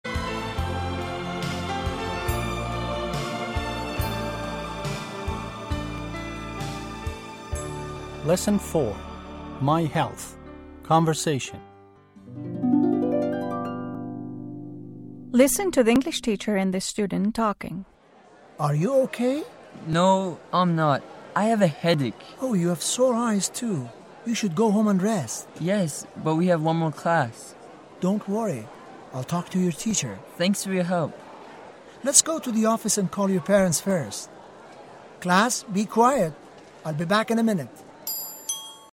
1-english-8-4-conversation.mp3